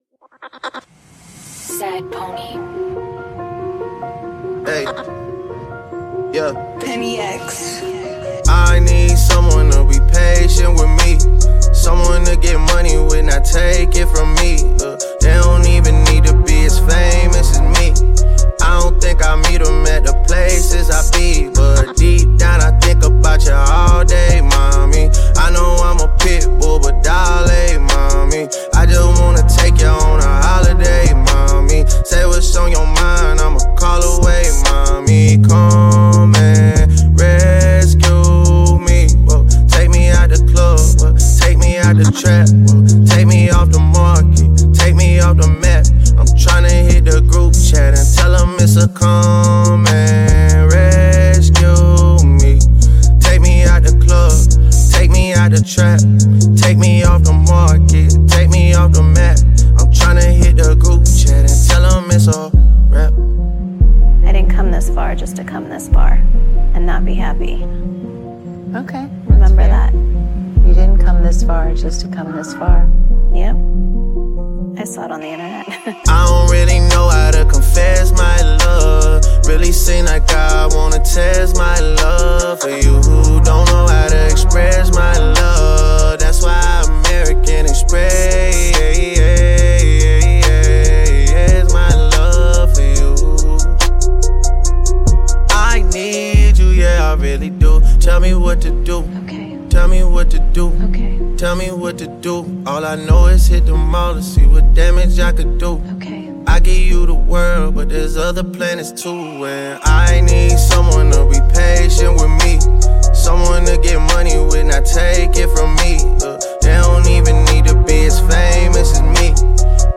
American rapper